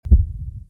UI Boom.wav